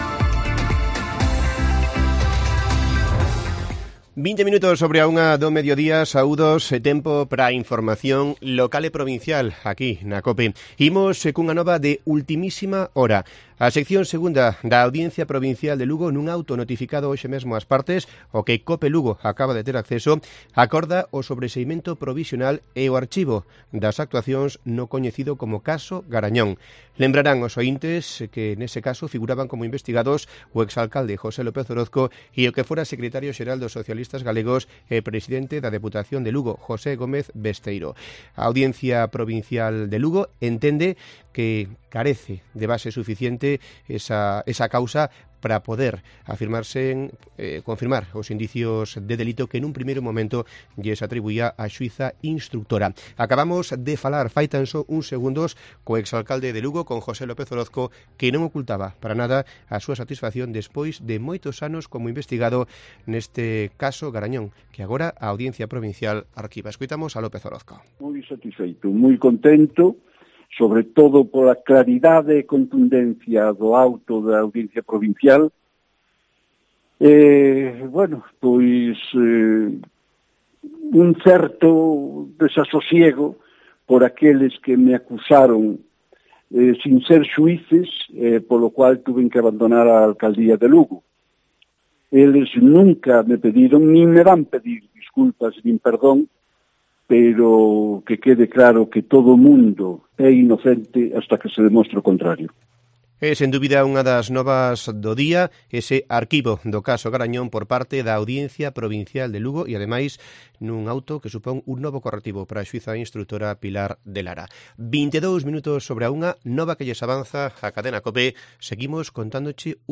Informativo Provincial de Cope Lugo. 03 de febrero. 13:20 horas